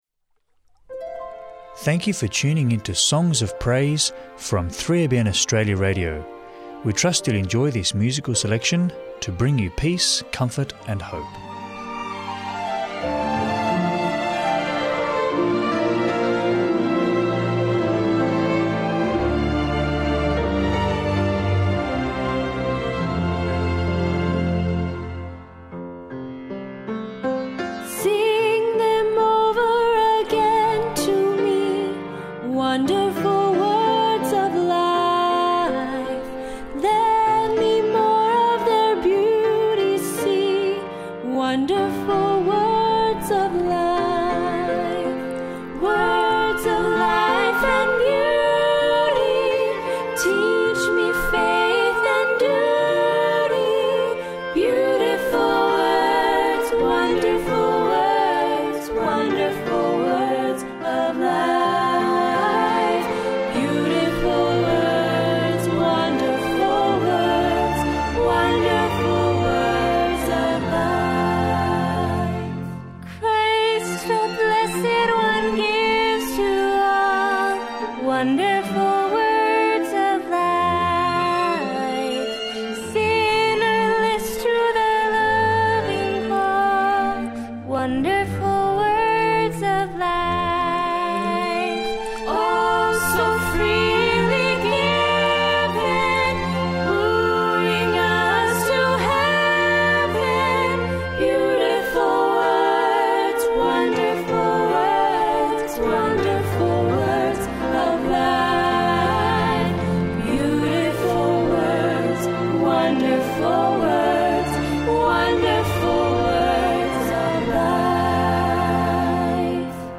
Enjoy uplifting Christian hymns and worship music